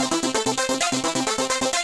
Index of /musicradar/8-bit-bonanza-samples/FM Arp Loops
CS_FMArp B_130-C.wav